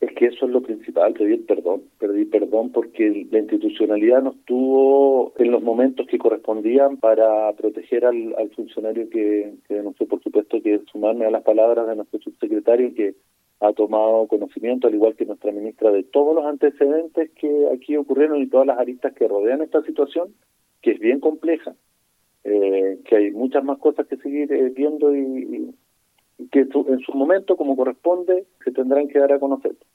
En entrevista con Radio Bío Bío en la ciudad, la autoridad sanitaria fue consultada por el ánimo que hay entre los trabajadores tras lo expuesto, replicando que hay “una consternación de toda la comunidad hospitalaria, esto nos ha pegado muy fuerte“.